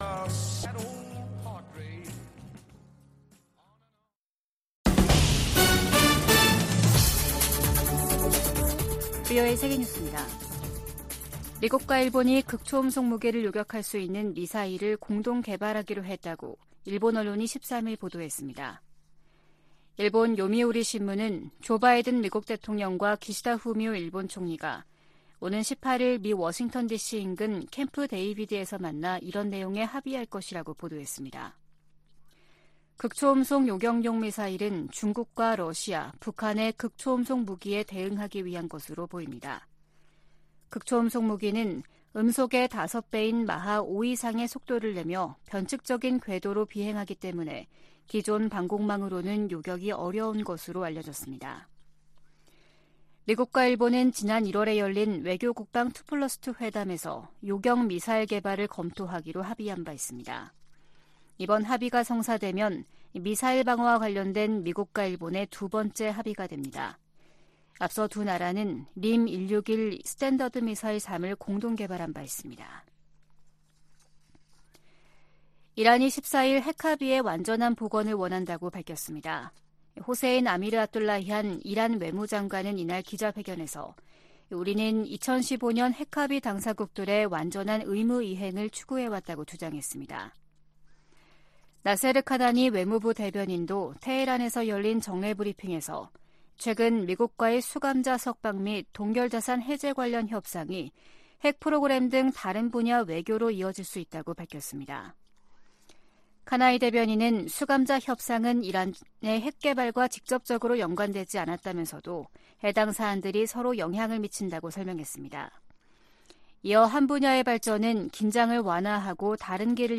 VOA 한국어 아침 뉴스 프로그램 '워싱턴 뉴스 광장' 2023년 8월15일 방송입니다. 북한이 악의적 사이버 활동을 통해 안보리 제재를 회피하고 있다고 유엔 주재 미국 대표가 지적했습니다. 미국 국방부는 11일 공개된 북한과 러시아 간 무기 거래 정황에 심각한 우려를 나타냈습니다. 오는 18일 열리는 미한일 정상회의에서 3국 군사훈련 정례화와 다양한 3국 간 협의체 구성 방안이 논의될 것으로 알려졌습니다.